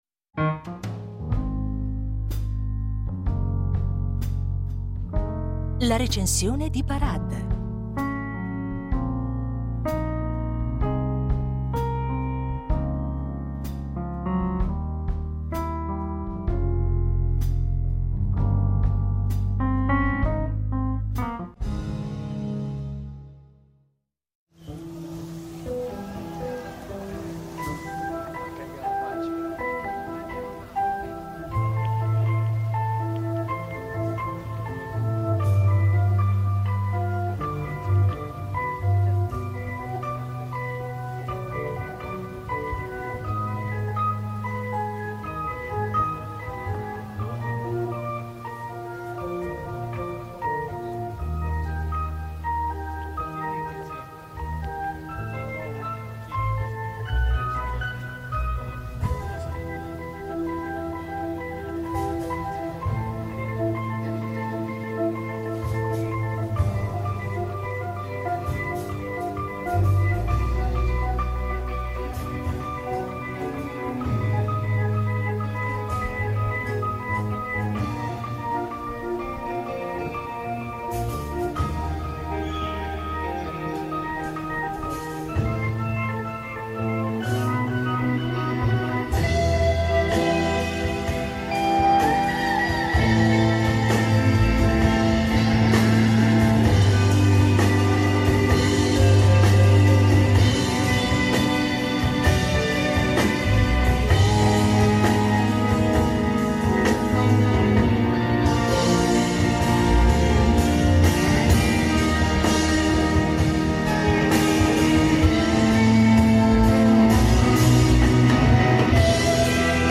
La Recensione